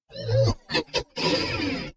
惊叹的哔哔声